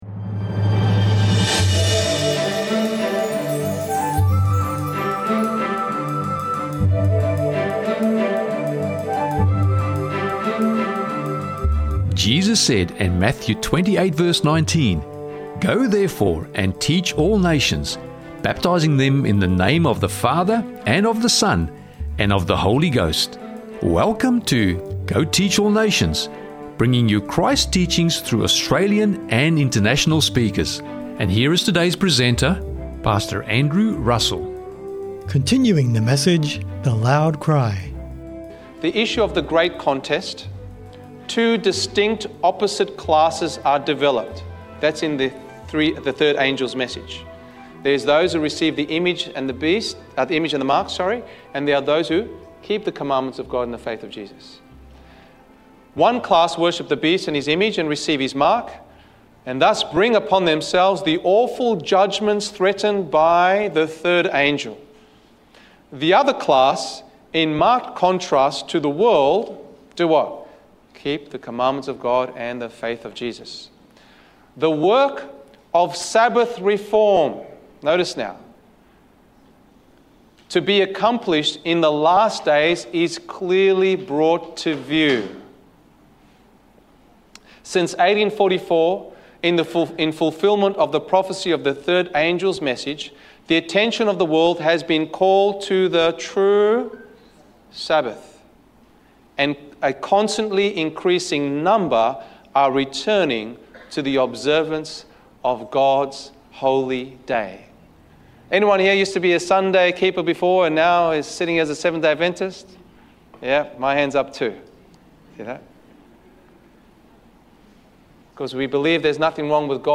The Loud Cry: Preparing for the Final Crisis pt 2 - Sermon Audio 2552